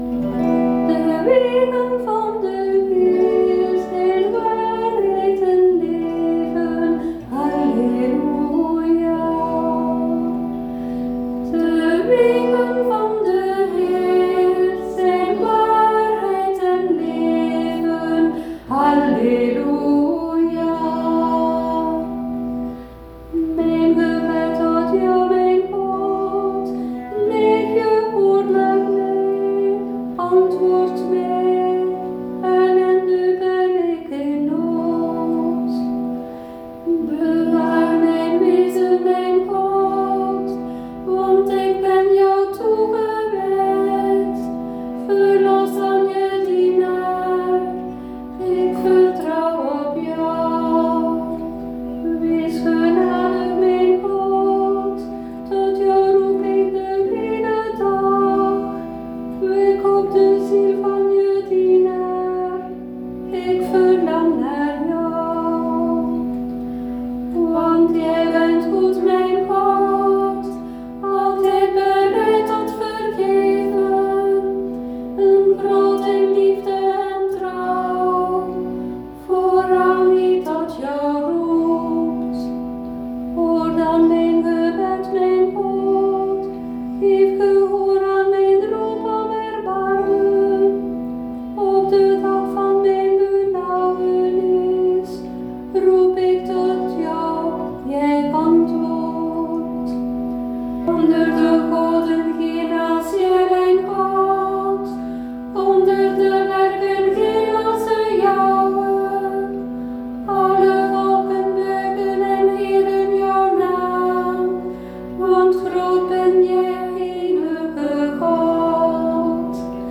met citerbegeleiding